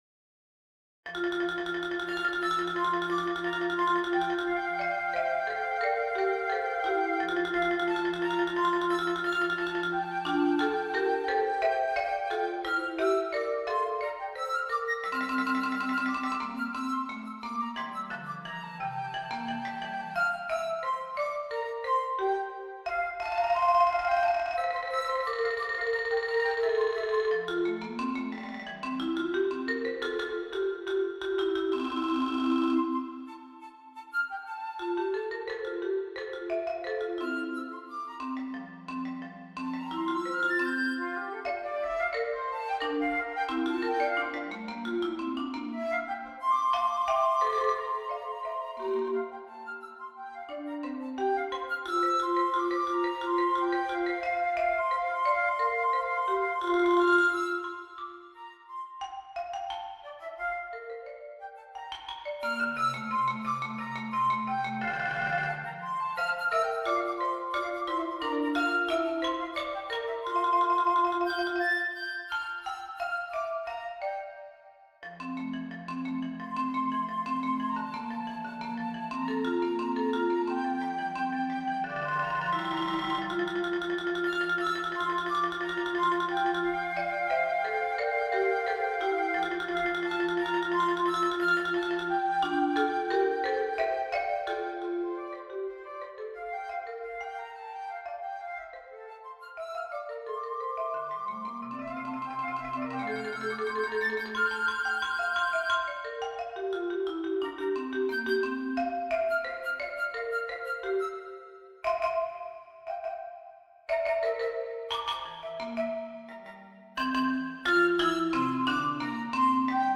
A fun and playful duet for Flute and Marimba.